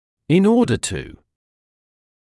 [ɪn ‘ɔːdə tuː][ин ‘оːдэ туː]для того, чтобы